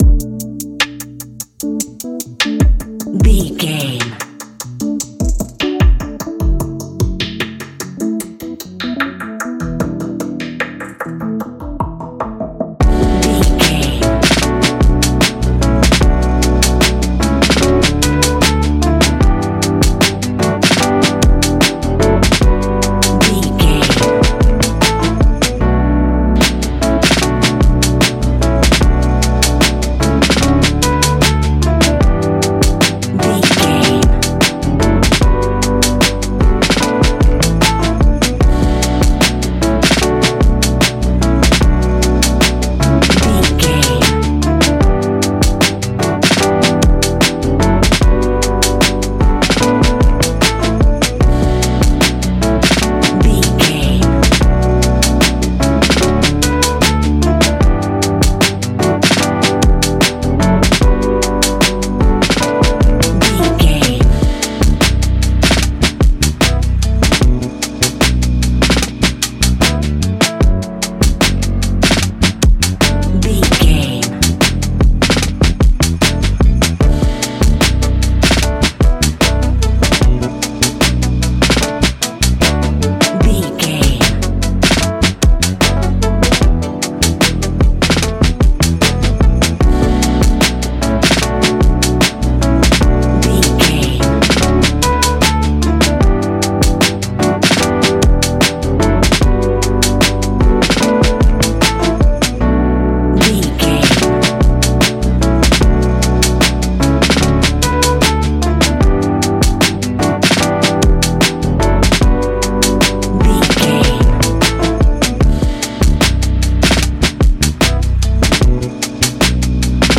Ionian/Major
B♭
chilled
laid back
Lounge
sparse
new age
chilled electronica
ambient
atmospheric